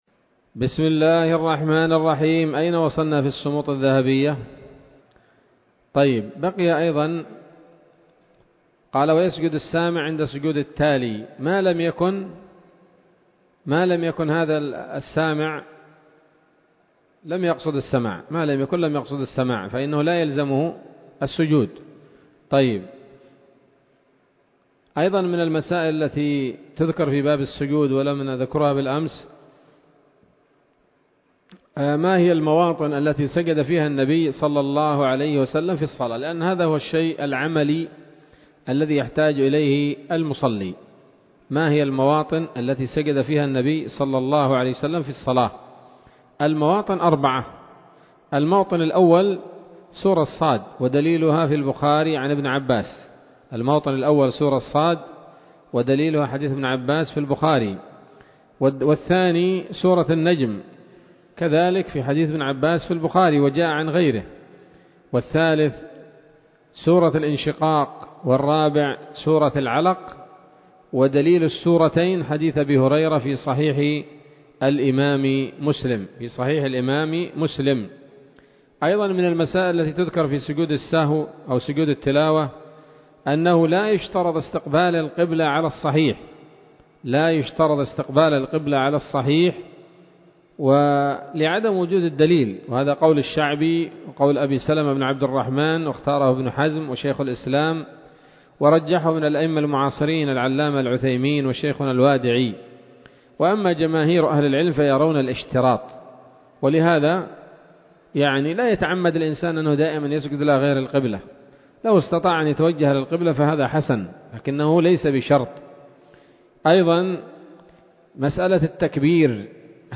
الدرس السادس والثلاثون من كتاب الصلاة من السموط الذهبية الحاوية للدرر البهية